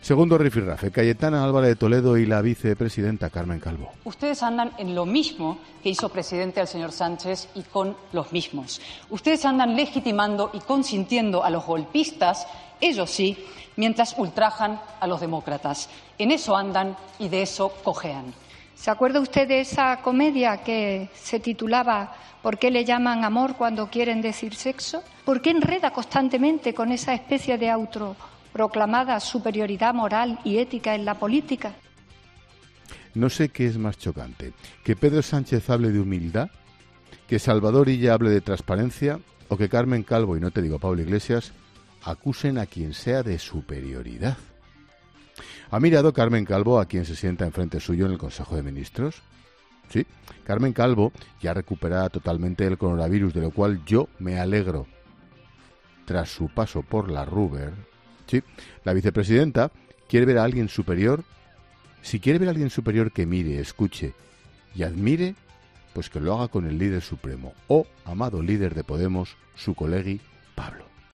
El presentador de ‘La Linterna’ ha valorado el cruce de declaraciones en el monólogo inicial del programa, quedándose especialmente con las palabras de la vicepresidenta primera del Gobierno.